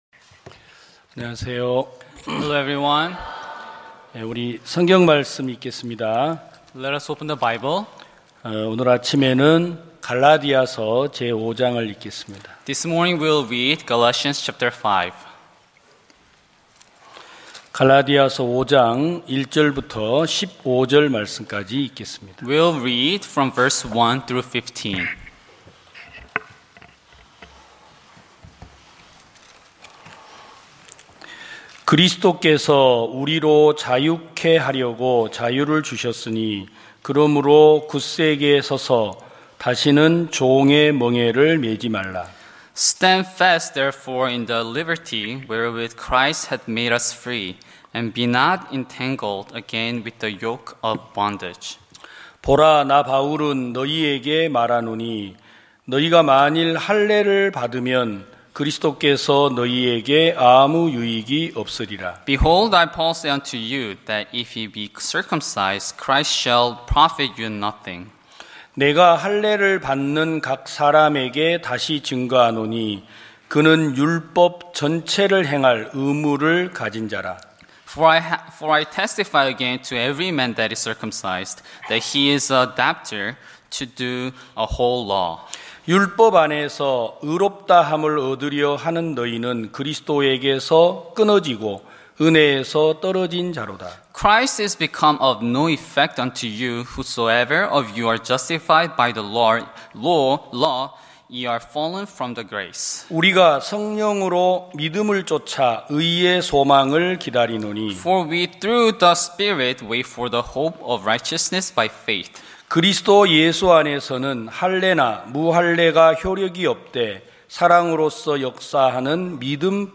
2021년 06월 06일 기쁜소식부산대연교회 주일오전예배
성도들이 모두 교회에 모여 말씀을 듣는 주일 예배의 설교는, 한 주간 우리 마음을 채웠던 생각을 내려두고 하나님의 말씀으로 가득 채우는 시간입니다.